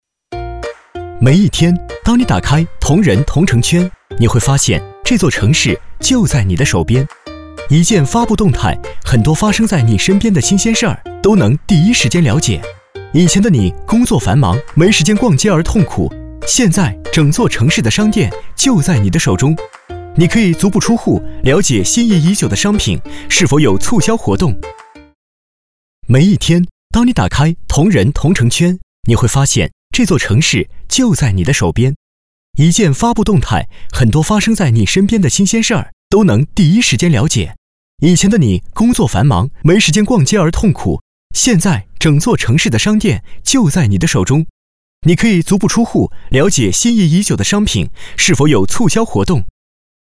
提供各类广告配音制作服务,如促销叫卖,品牌广告,清仓叫卖,彩铃等。
男39 app铜仁同城圈（轻松）.mp3